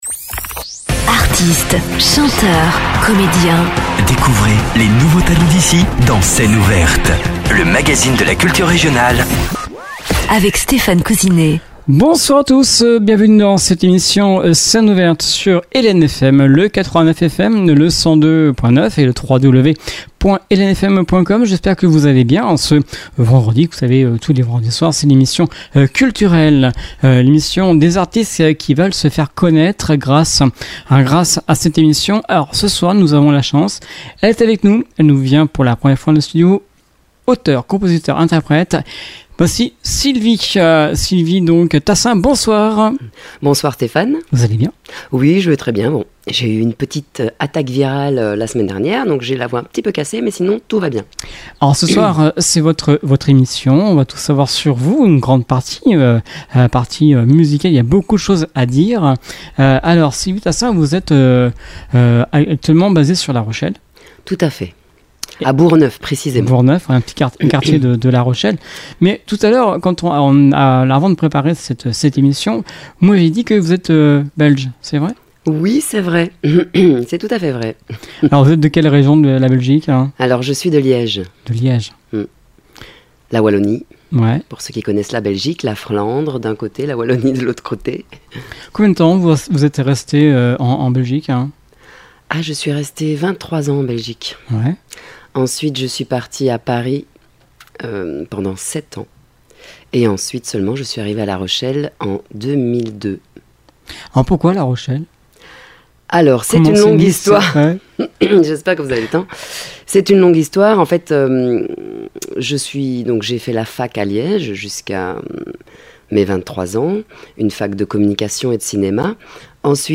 Elle chante aussi des reprises et ses propres compositions.